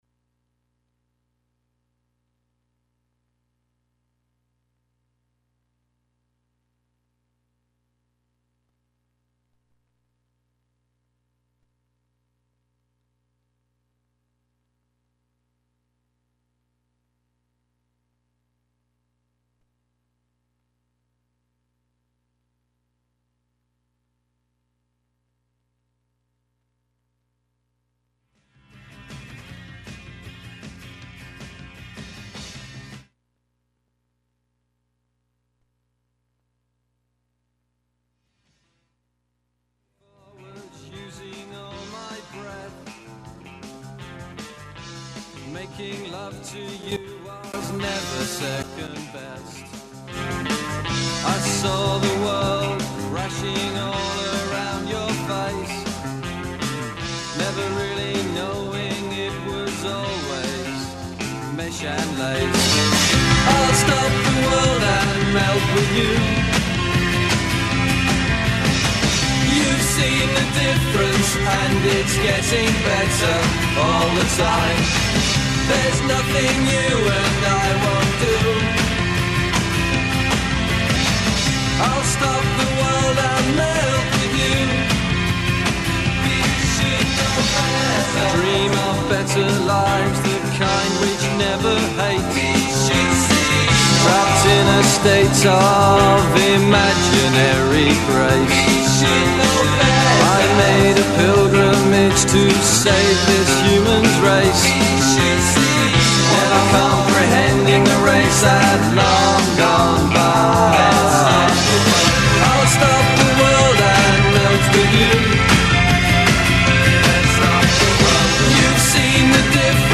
On this show, you’ll hear recent news, personal experiences, and a diverse selection of music. Youth Radio Raw is a weekly radio show produced by Bay Area high schoolers, ages 14-18.